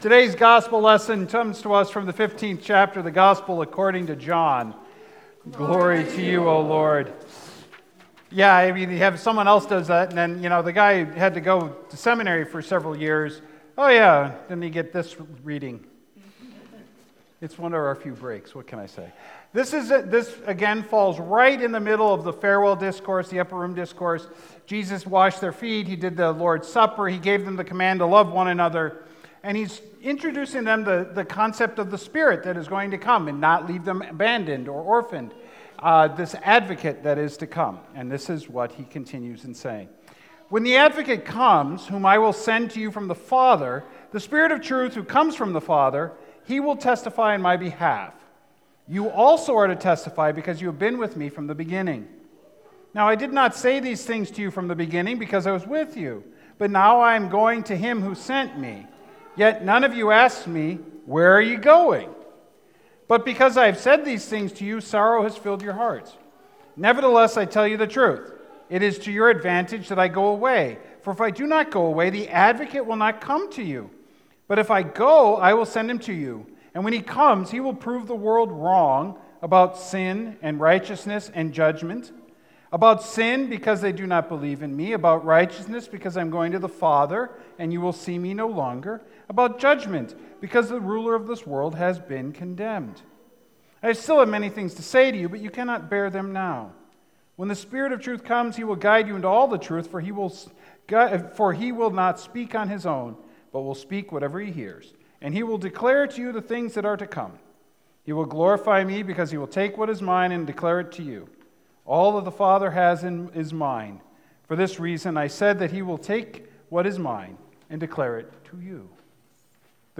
Sermons | Beautiful Savior Lutheran Church
Day of Pentecost